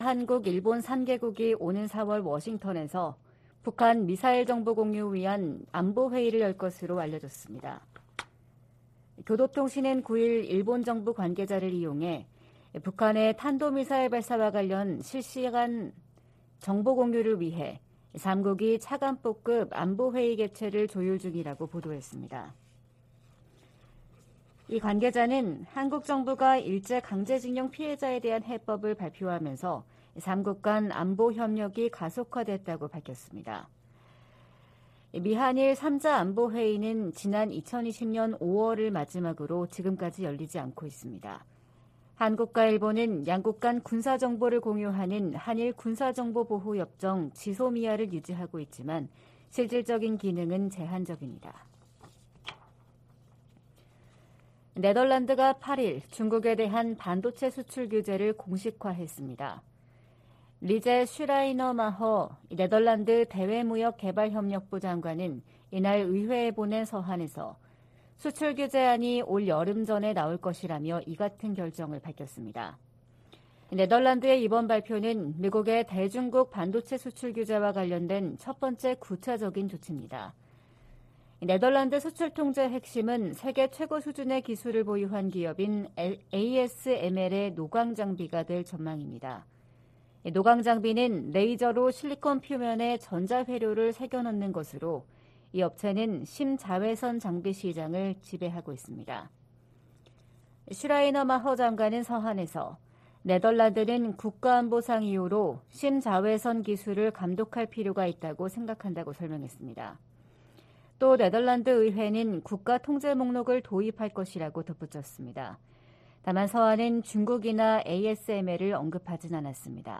VOA 한국어 '출발 뉴스 쇼', 2023년 3월 10일 방송입니다. 윤석열 한국 대통령이 오는 16일 일본을 방문해 기시다 후미오 총리와 정상회담을 갖는다고 한국 대통령실이 밝혔습니다. 미 국무부는 미한일 3자 확장억제협의체 창설론에 대한 입장을 묻는 질문에 두 동맹국과의 공약이 철통같다고 밝혔습니다. 권영세 한국 통일부 장관은 미래에 기초한 정책을 북한 지도부에 촉구했습니다.